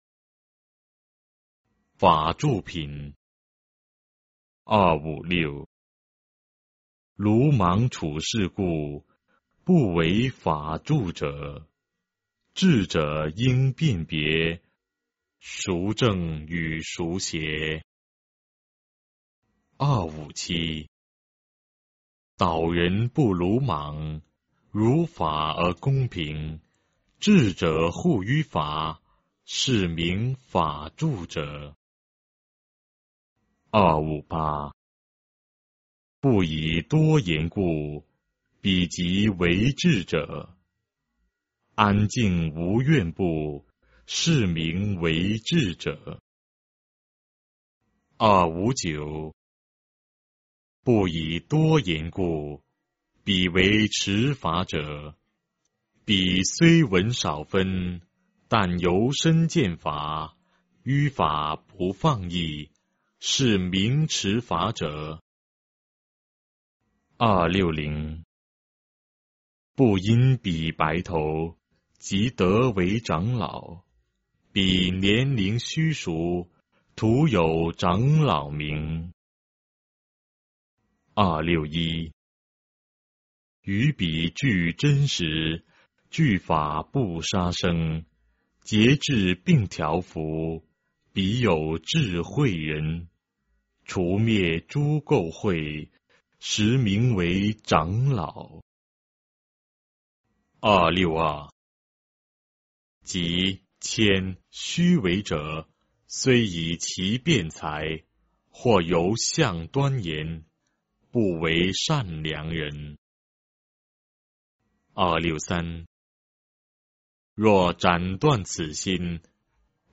法句经-法住品 诵经 法句经-法住品--未知 点我： 标签: 佛音 诵经 佛教音乐 返回列表 上一篇： 法句经-地狱品 下一篇： 金刚经-念诵 相关文章 悉发菩提心--佛光山梵呗 悉发菩提心--佛光山梵呗...